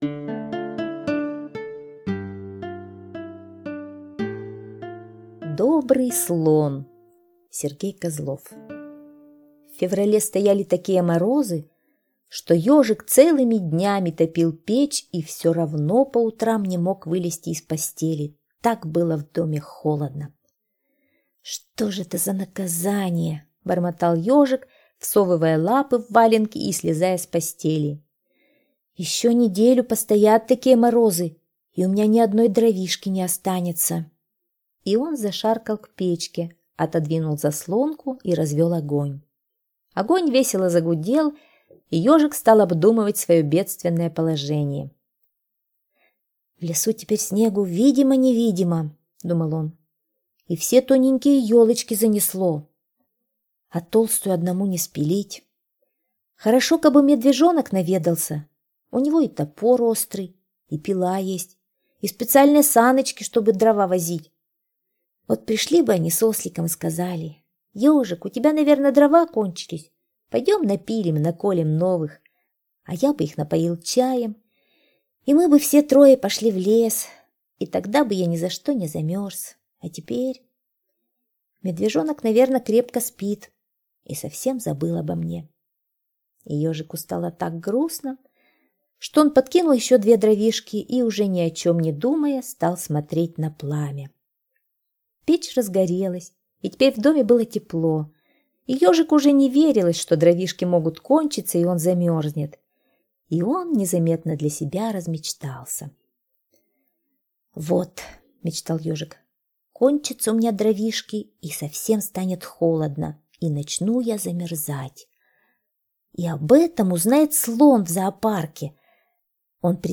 Слушайте Добрый слон - аудиосказка Козлова С.Г. Сказка про Ежика, у которого в долгие февральские морозы закончились дрова и он замерзал у себя в домике.